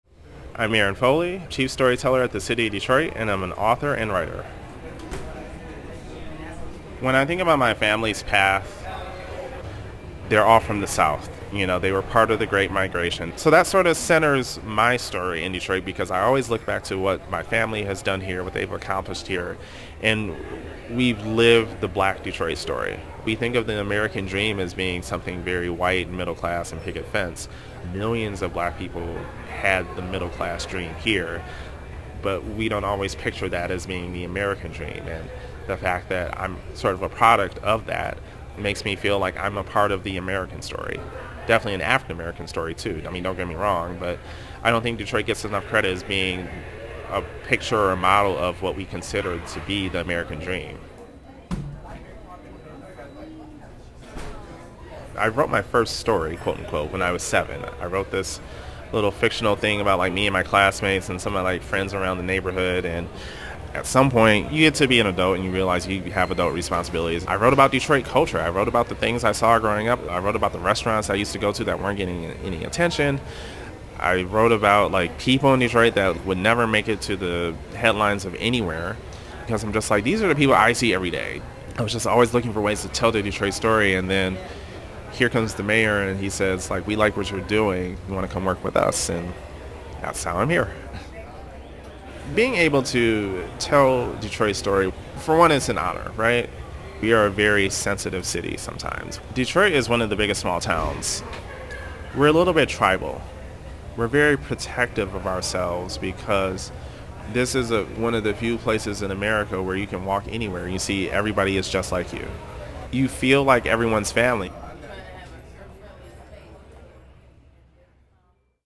Oral histories (literary works)